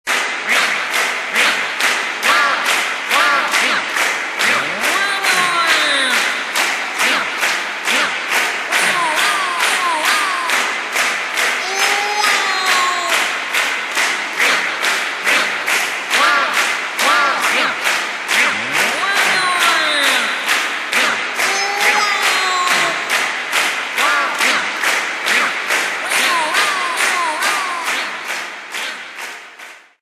Description Clapping
Source Ripped